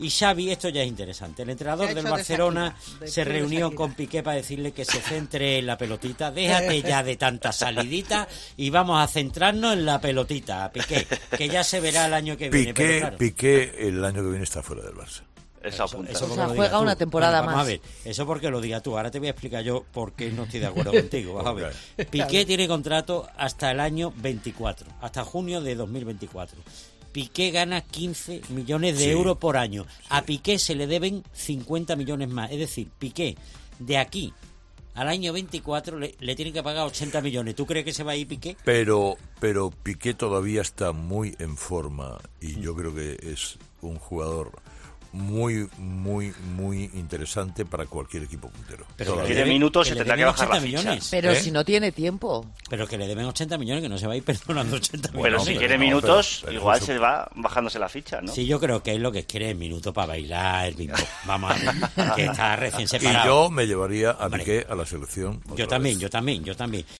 Un pronóstico que puedes escuchar en el siguiente audio, en el que Herrera relata qué cree que puede depararle el futuro a uno de los futbolistas más importantes de la historia reciente del Barça y de la Selección Española.